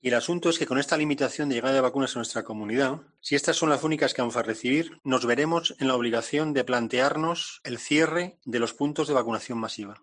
Antonio Zapatero, coordinador del Plan COVID, avierte de un posible cierre de centros por falta de dosis